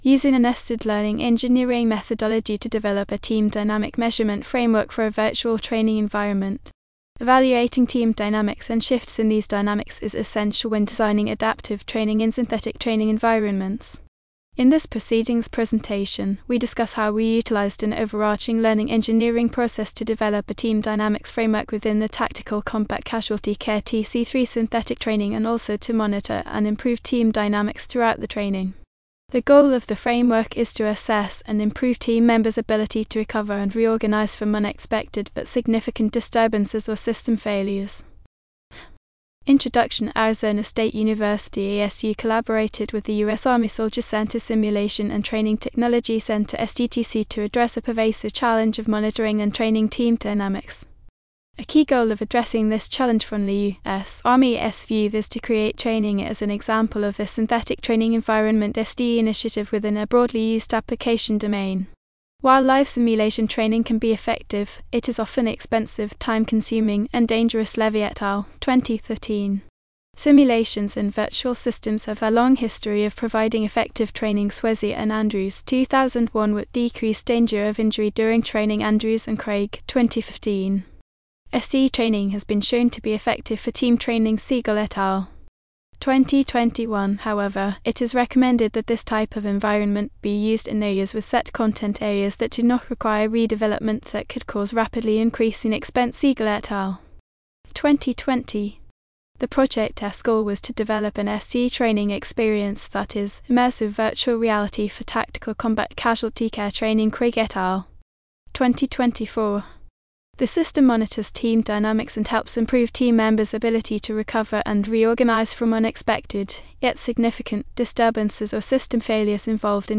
In this proceedings presentation, we discuss how we utilized an overarching Learning engineering process to develop a team dynamics framework within the Tactical Combat Casualty Care (TC3) synthetic training and also to monitor and improve team dynamics throughout the training. The goal of the framework is to assess and improve team members’ ability to recover and reorganize from unexpected but significant disturbances or system failures.